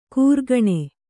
♪ kūrgaṇe